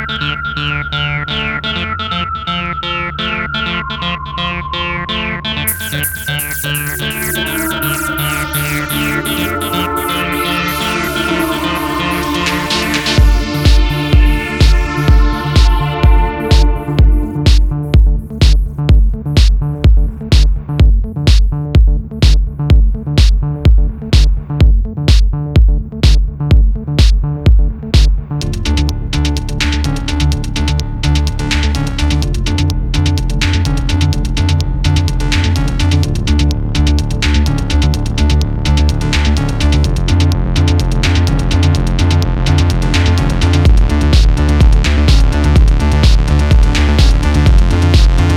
MASTERED.wav